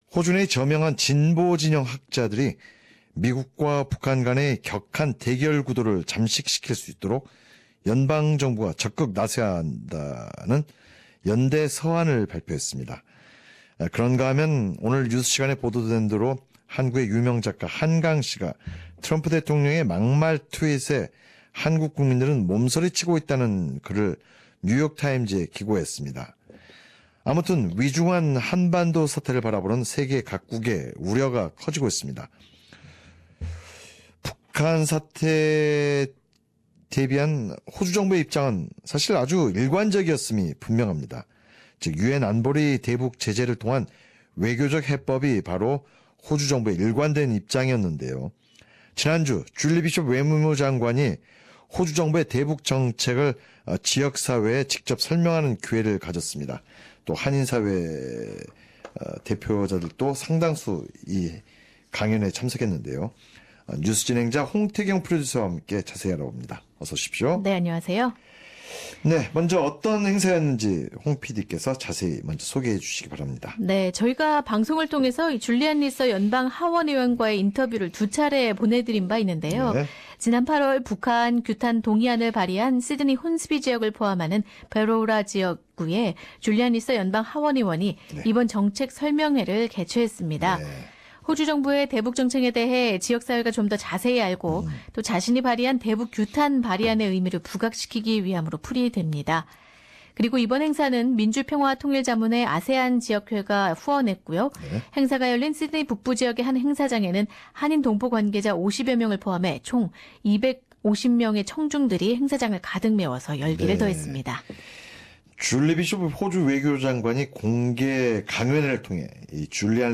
북핵 사태에 대해 외교적 해법을 일관되게 주장해온 줄리 비숍 호주외교장관이 민주평화통일자문회의 아세안 지역회의의 후원으로 열린 공개 강연회에서 호주의 대북정책과 향후 방향에 대해 설명했다.
Foreign Affairs Minister Julie Bishop talks about North Korean issues.